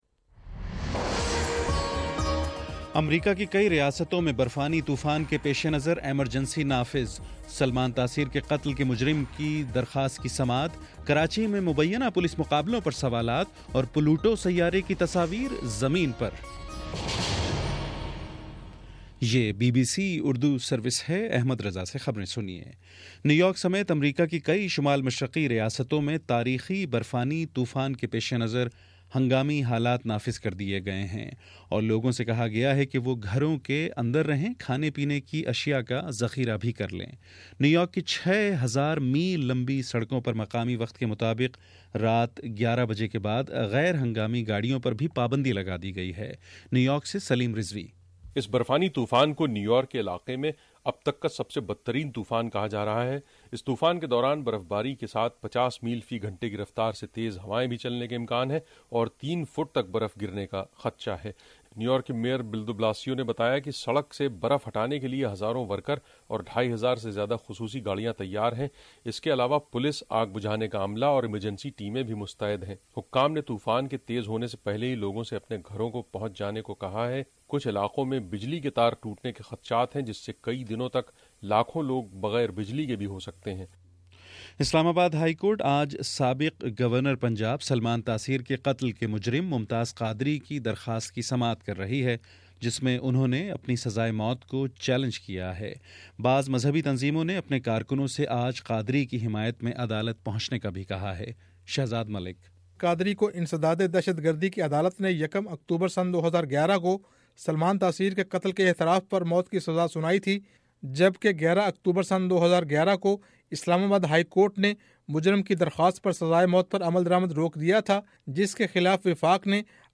جنوری 27: صبح نو بجے کا نیوز بُلیٹن